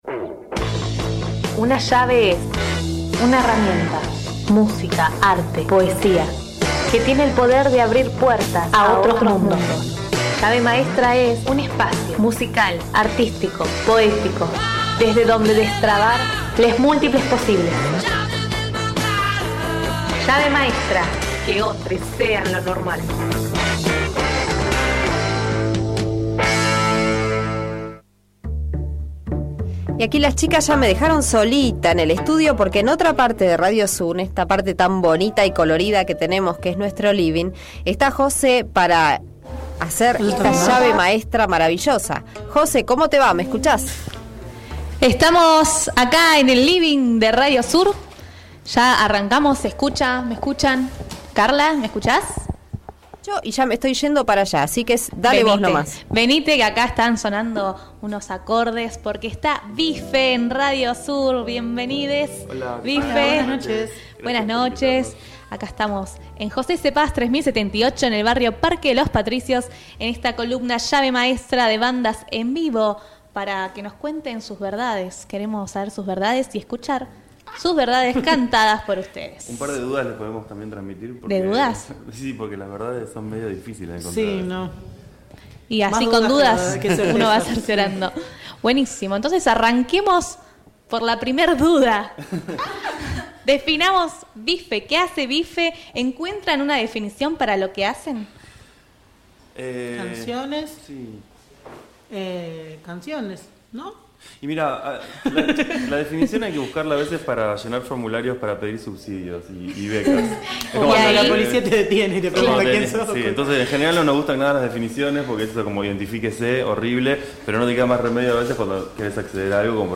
Dentro del ciclo Llave maestra, en Graves y agudas nos proponemos difundir música alternativa e independiente. Este mes nos visitó el dúo BIFE.
BIFE es un dúo musical con una propuesta fiestera, ritmos latinos y actitud punk.